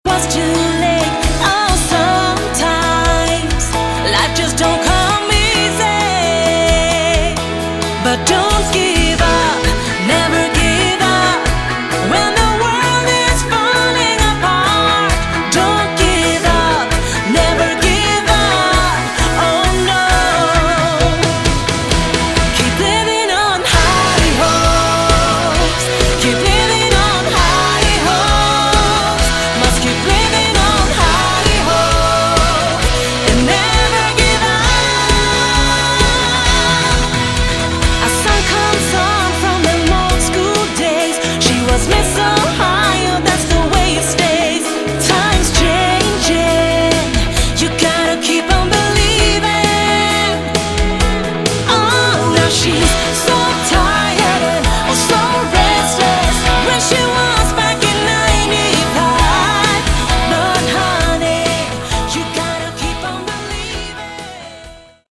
Category: Melodic Rock
lead vocals
guitars, bass
acoustic guitars
keyboards, backing vocals, drums